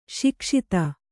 ♪ śikṣita